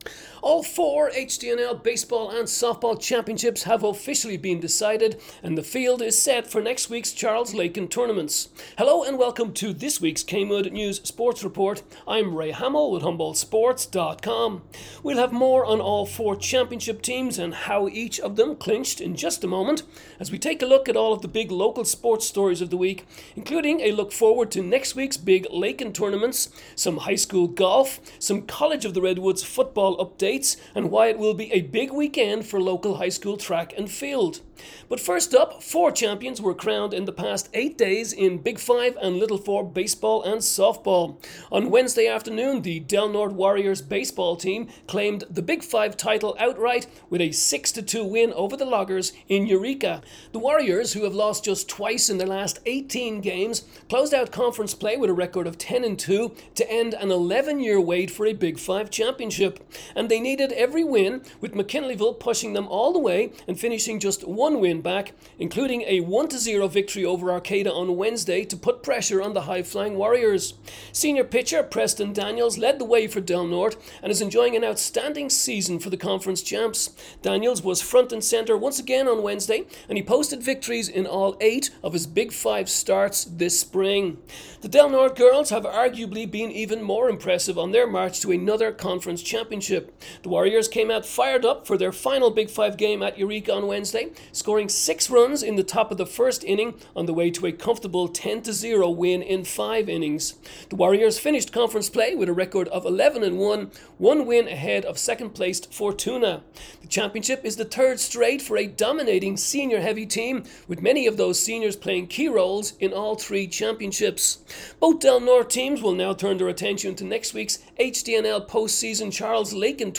KMUD News Sports Report May 8